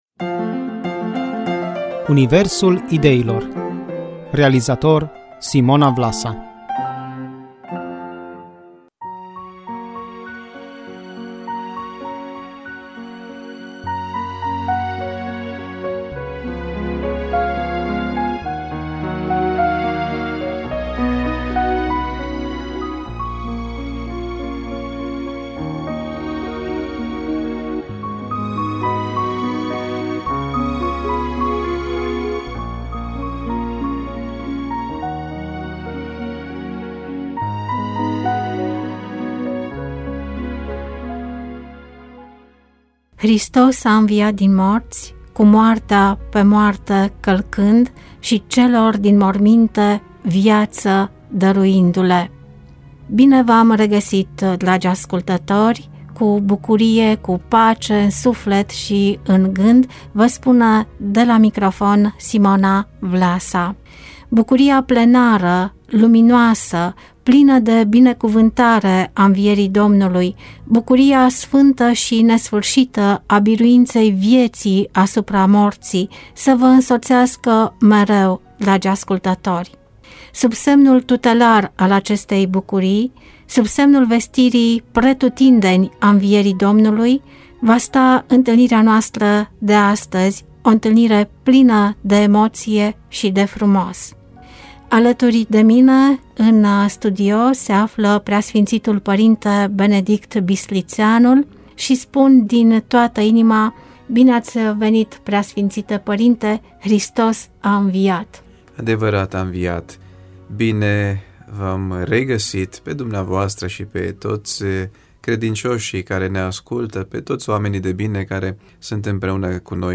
Înviere și Solidaritate, dialog în lumina Învierii cu PS Episcop Benedict